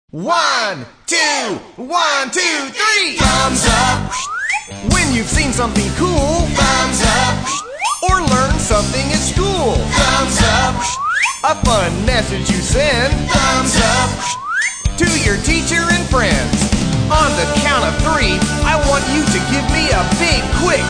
Action Song for Young Children
It’s short and sweet and fun for the kids.